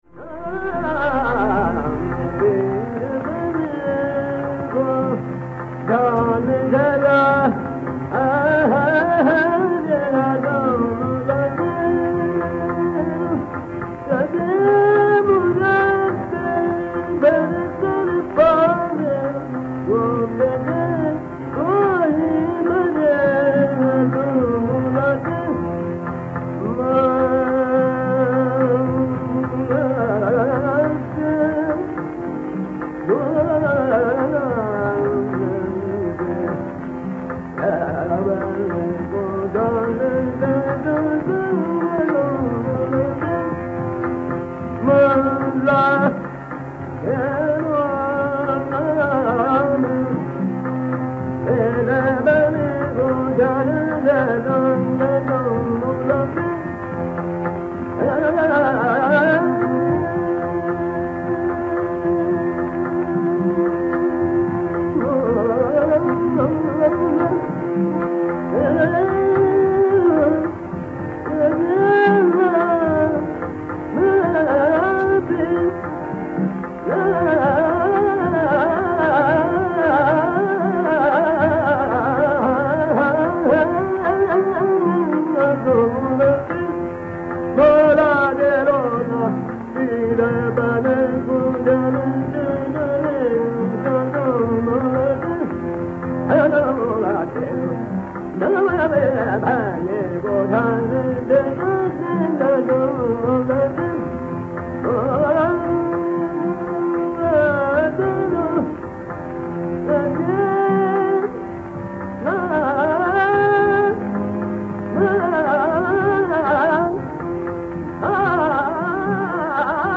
Raga Jaunpuri
Jaunpuri is often rendered with a lightness of touch in contrast to the solemn Asavari.
Ramkrishnabuwa Vaze breezes through as only he could.